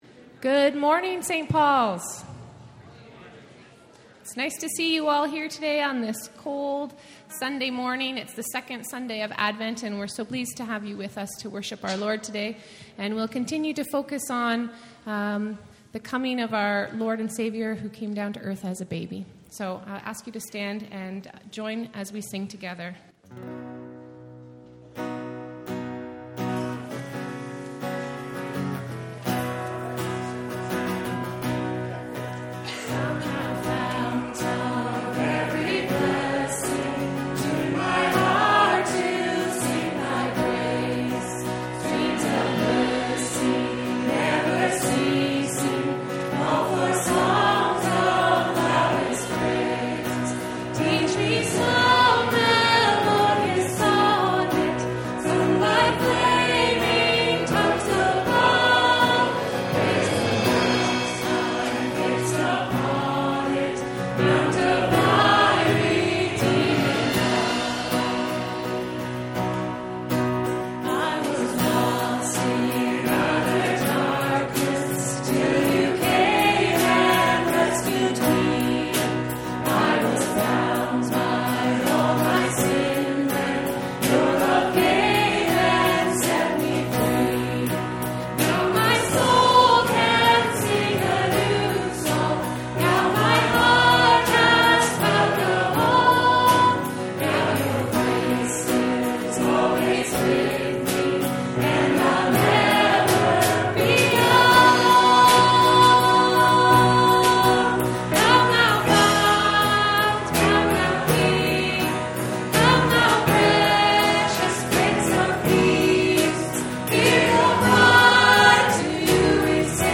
A message from the series "Fall 2019."